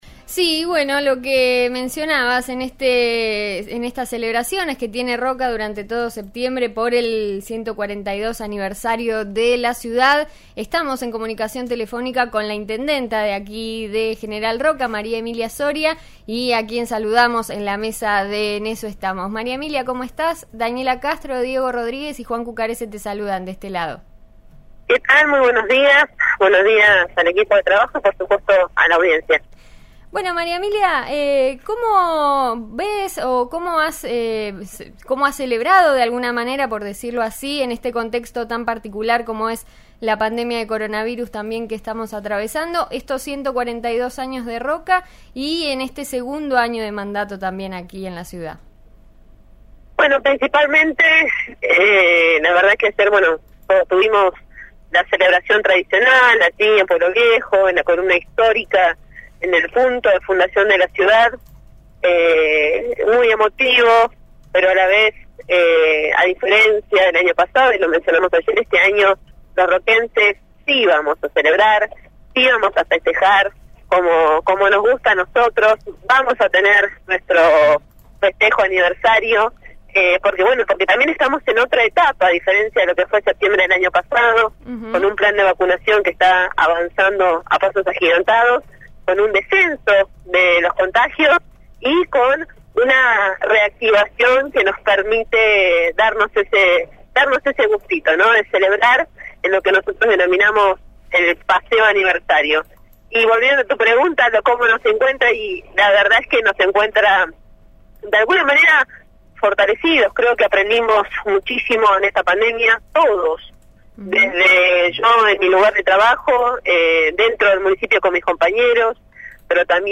María Emilia Soria, intendenta de Roca, dialogó esta mañana con el programa En Eso Estamos de RN Radio. La charla se dio en el marco de un nuevo aniversario de la ciudad. En la entrevista la jefa comunal se refirió a distintos temas de la actualidad y habló sobre la posibilidad de celebrar la próxima Fiesta Nacional de la Manzana, suspendida este año por la pandemia de coronavirus.
Maria-Emilia-Soria-Intendenta-de-Roca.mp3